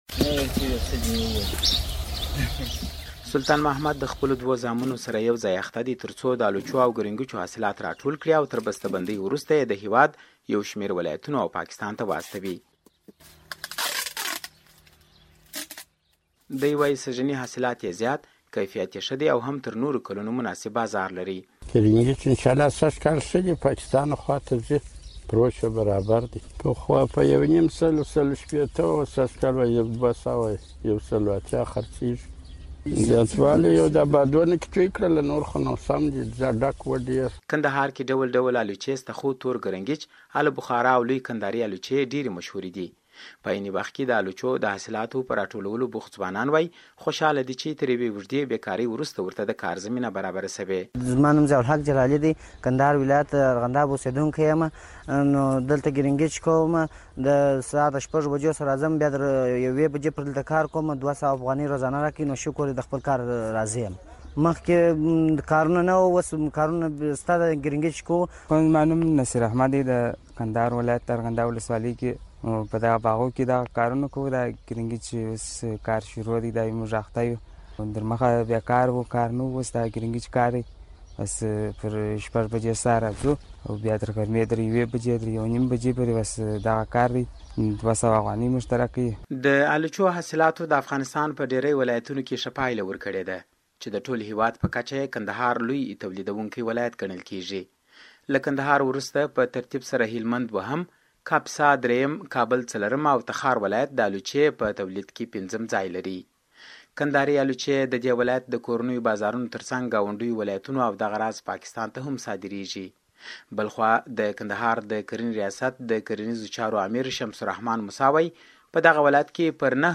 د الوچو راپور